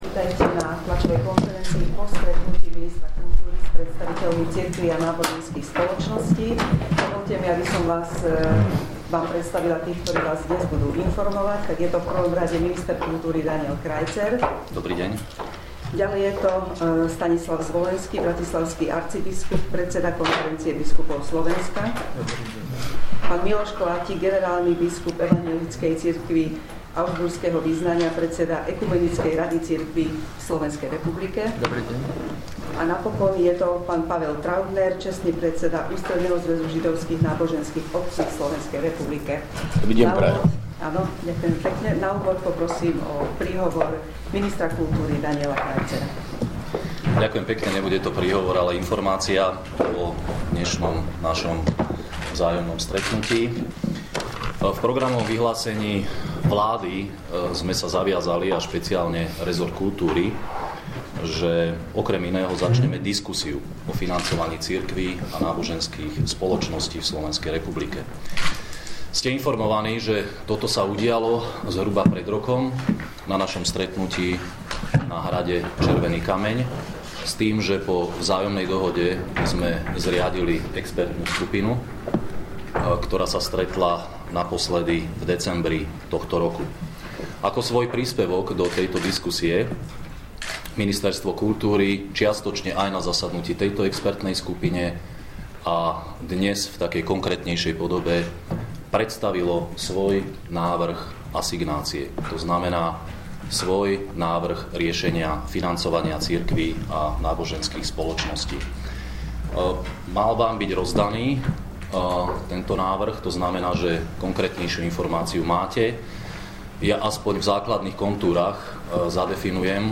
Tla�ov� konferencia na MK SR